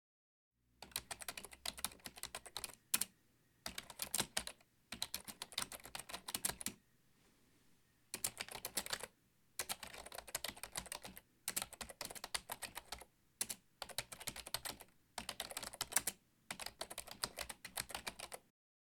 Keyboard_Typing
DR-40 Fast Keyboard Tascam Typing sound effect free sound royalty free Memes